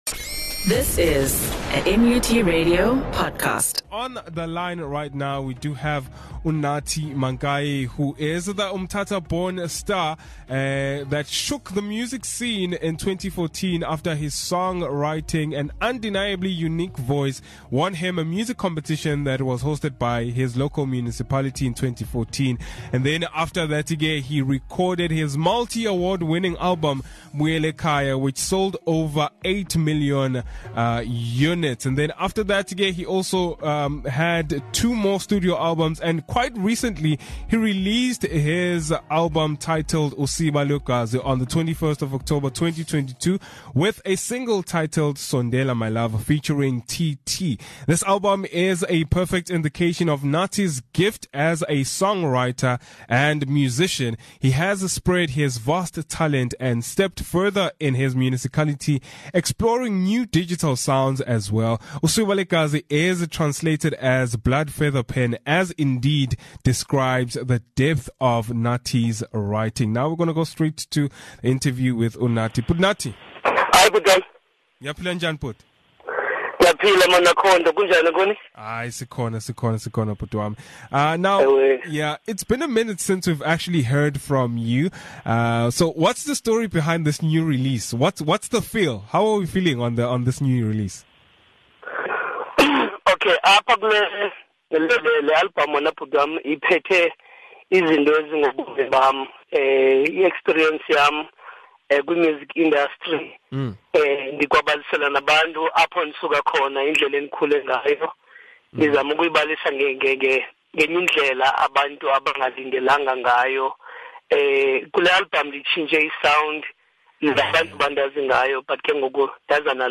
The Vukubangene Breakfast show had an Interview with Nathi Mankayi on his new album release titled Usiba LweGazi,Speaking on the story behind it and him exploring new sound,featuring an upcoming artist and more.